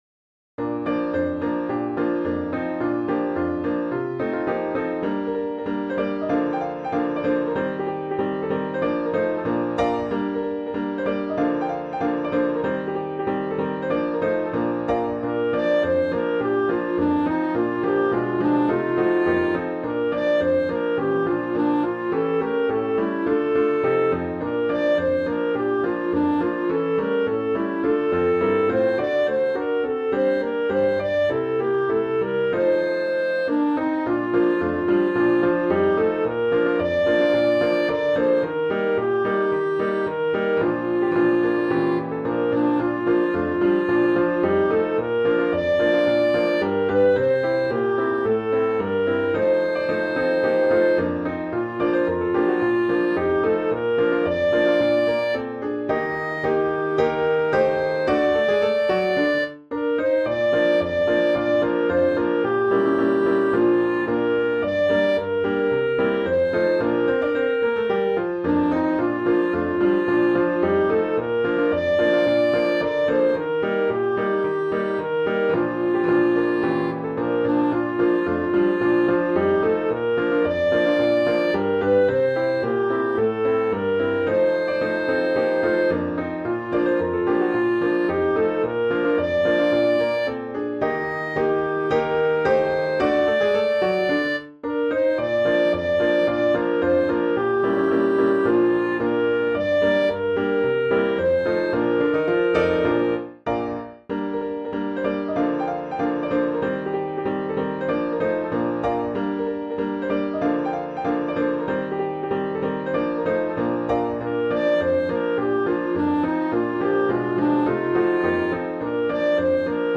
music hall song